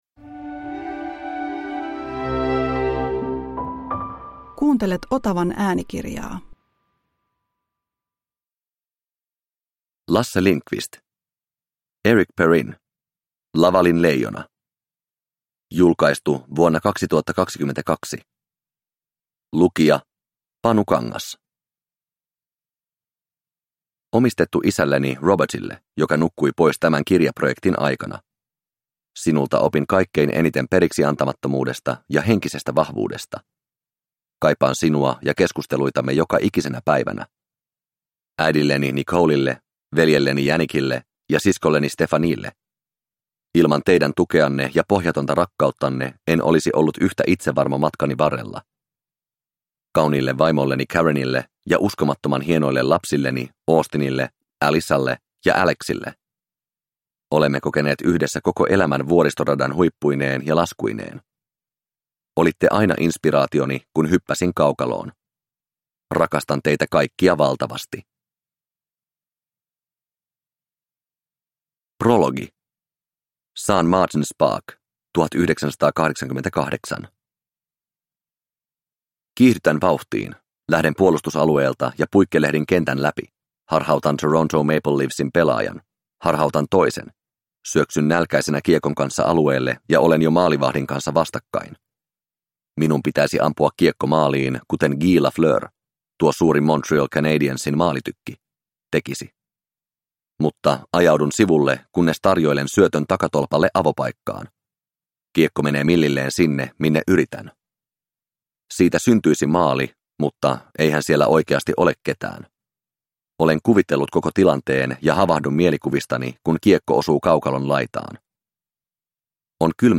Éric Perrin - Lavalin leijona – Ljudbok – Laddas ner